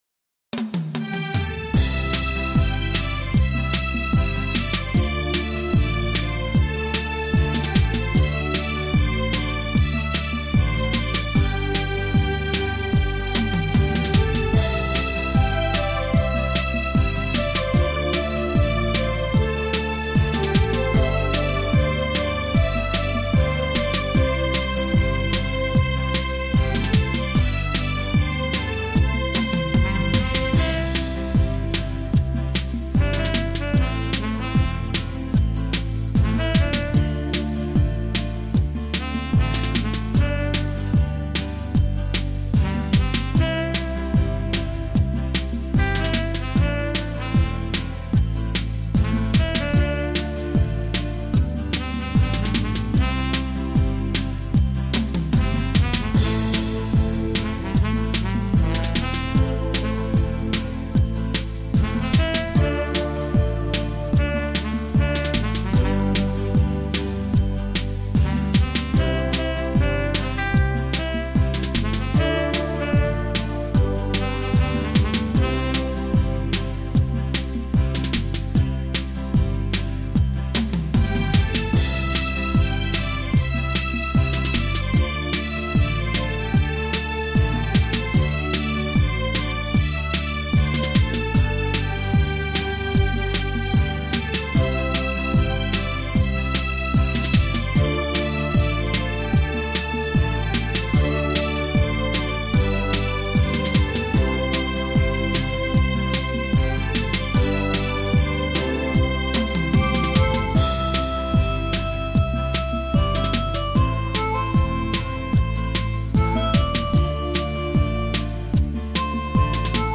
甜蜜的歌声 精良的制作 让你一饱耳福